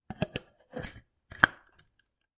Звуки кофемолки
Закрыли крышку у кофемолки